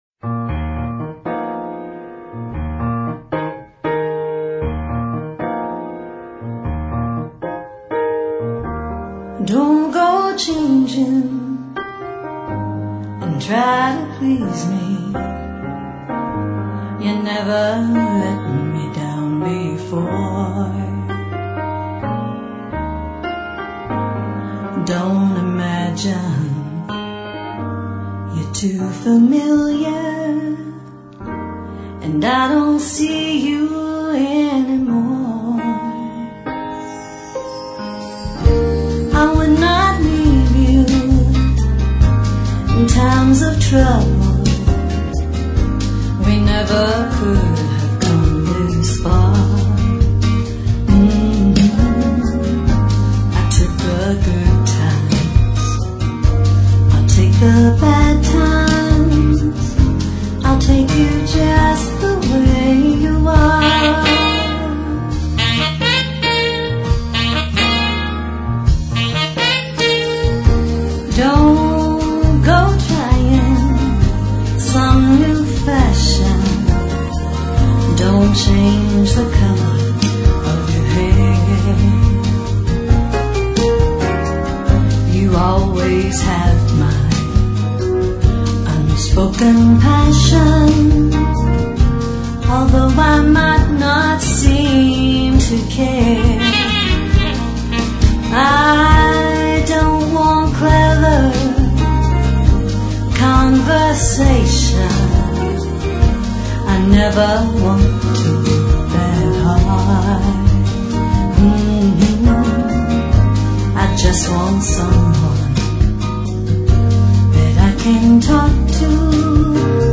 Acoustic Guitar Songs